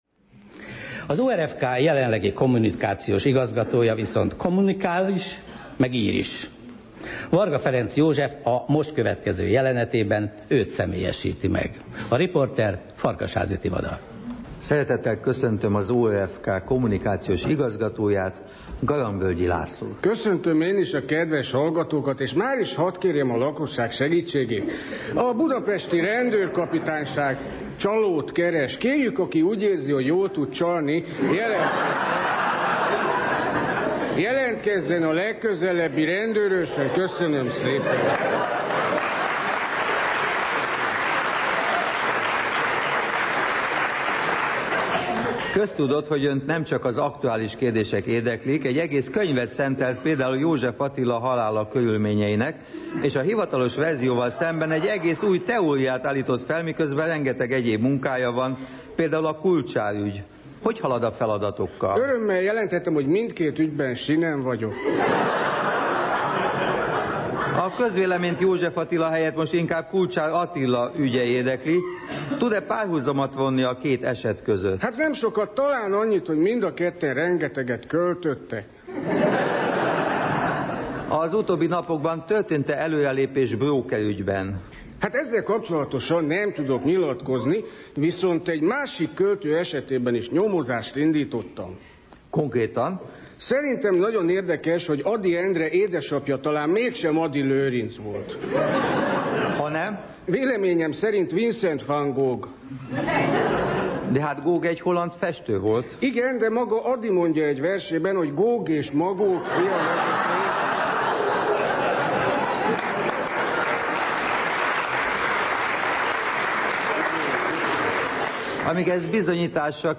Az alábbi felvételek a Magyar Rádióban készültek.